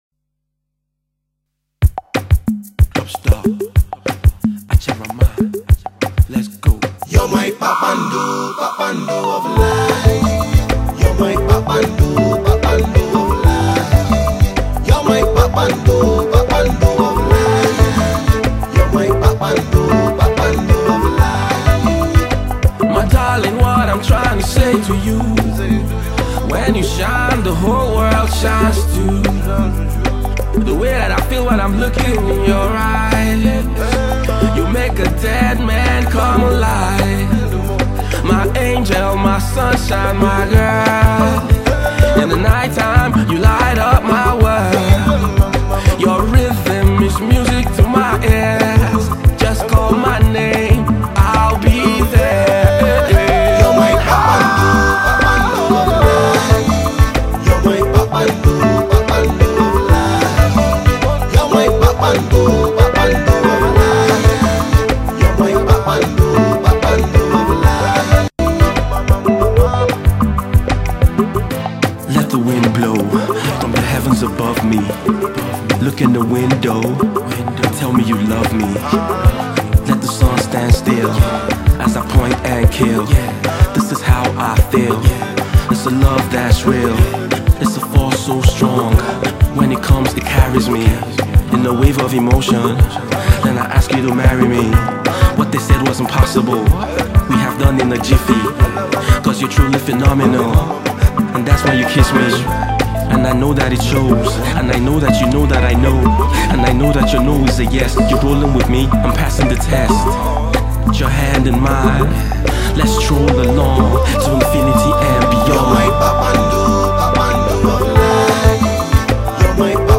Pop
a sweet flowing tune, a melodious love song
sweet African percussion